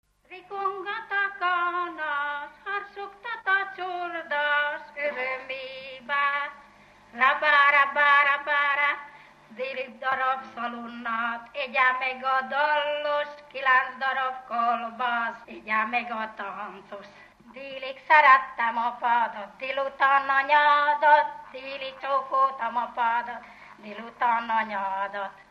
Dunántúl - Esztergom vm. - Bajna
Stílus: 3. Pszalmodizáló stílusú dallamok
Szótagszám: 12.12.12
Kadencia: 5 (b3) 1